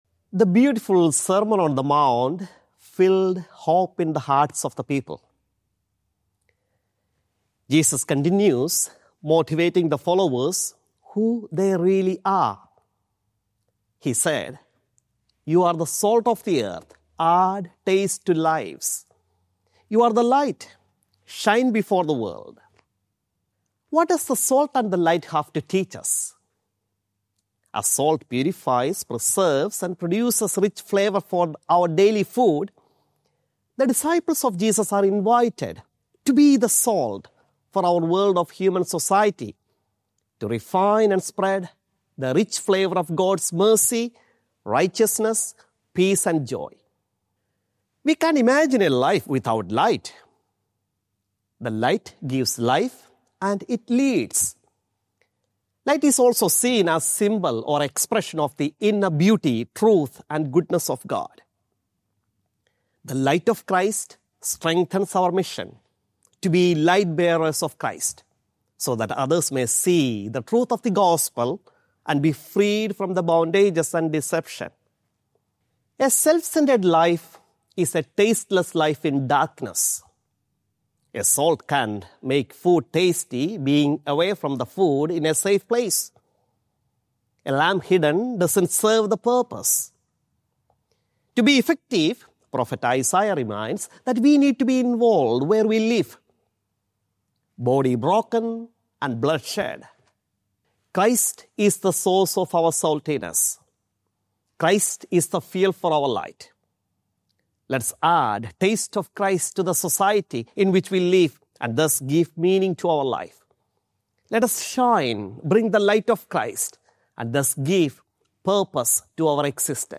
Archdiocese of Brisbane Fifth Sunday in Ordinary Time - Two-Minute Homily